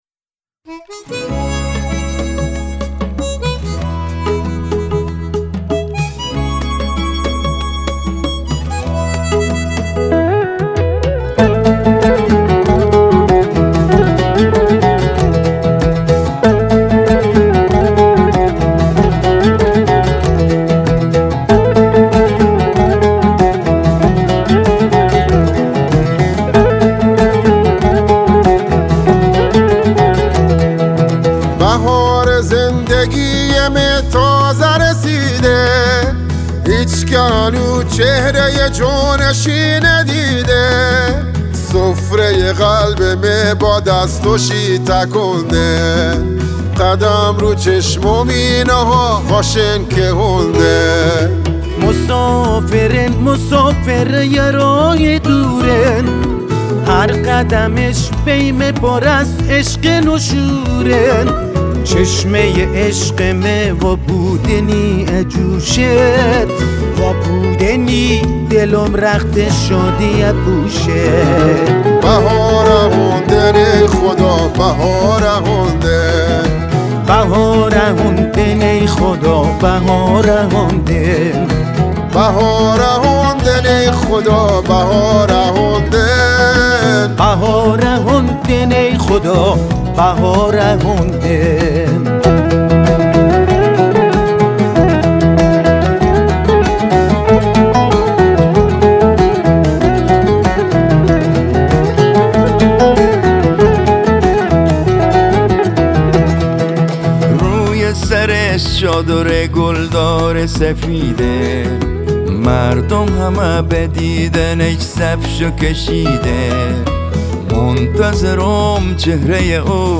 گیتار و گیتار باس
هارمونیکا
عود
سازهای کوبه ای